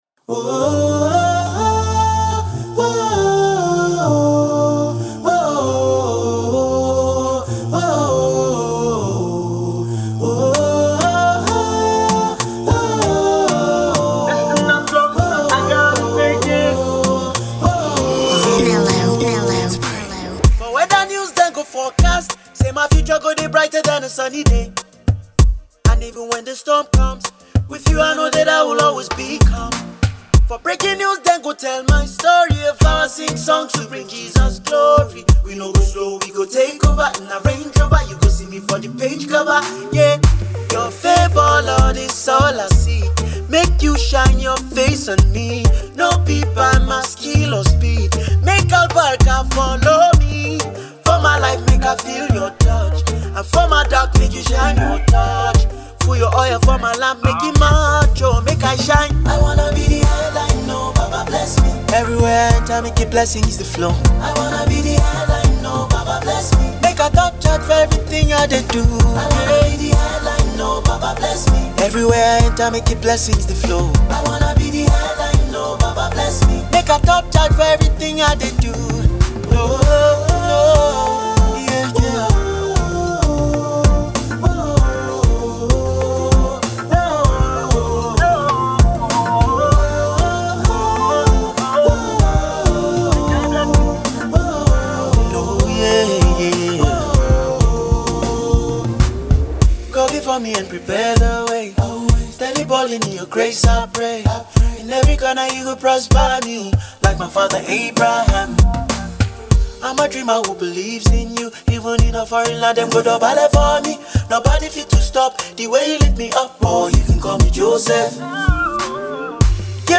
A vibe and a prayer all in one song